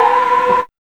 2504R CHORD.wav